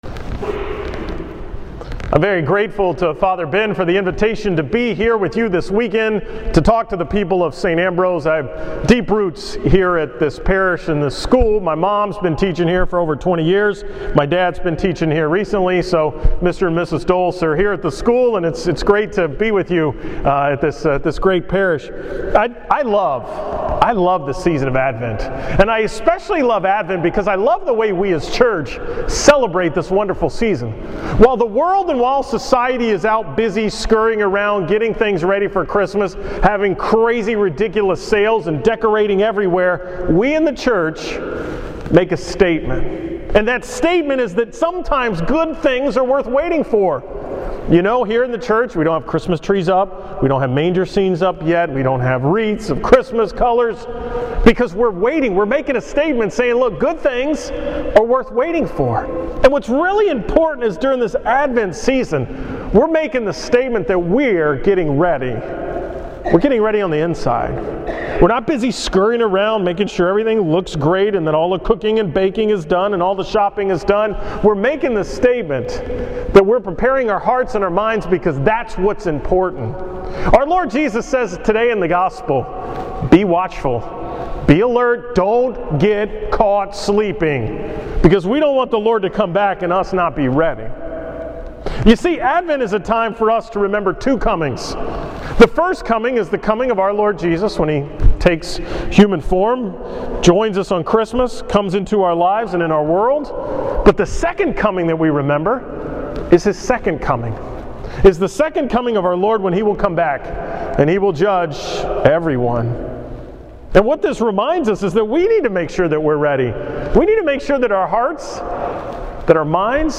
From the 10 am Mass at St. Ambrose on November 30, 2014